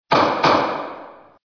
Gavel Strike
Category: Sound FX   Right: Personal